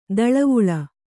♪ daḷavuḷa